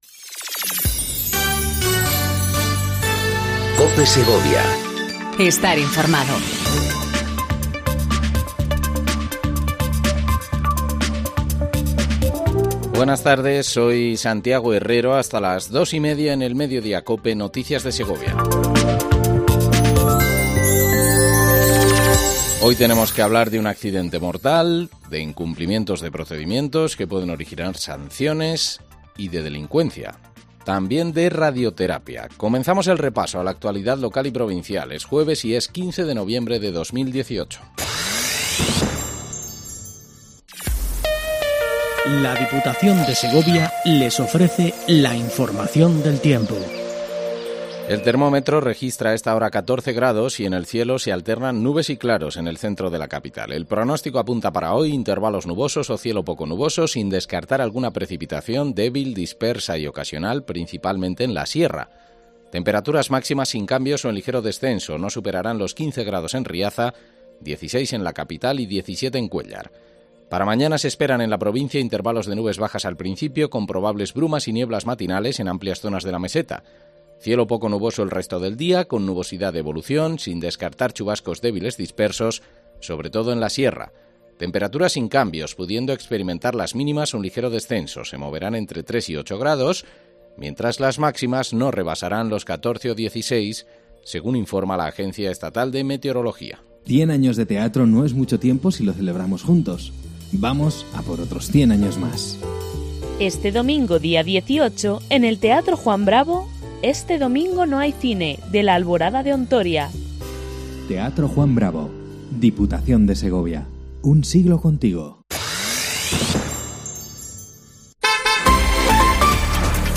INFORMATIVO MEDIODÍA COPE SEGOVIA 14:20 DEL 15/11/18